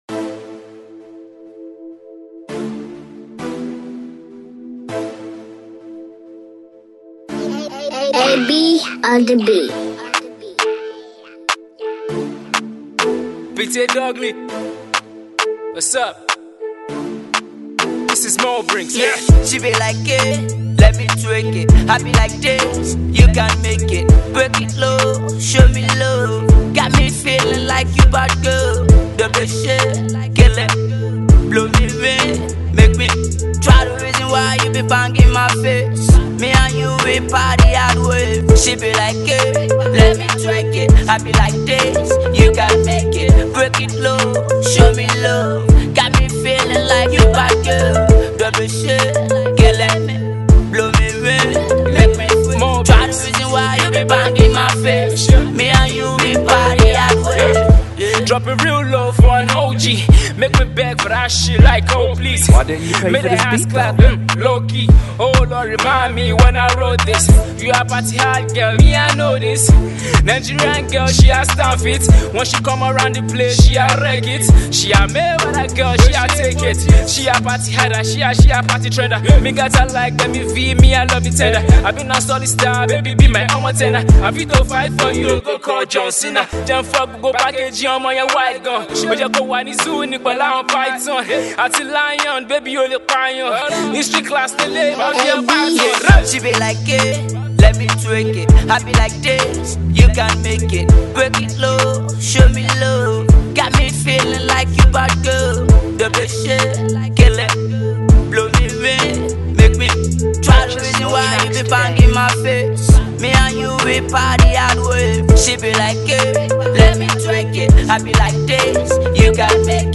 mixtape
all your favorite hits back to back